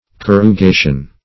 Corrugation \Cor`ru*ga"tion\ (k?r`r?-g?"sh?n), n. [Cf. F.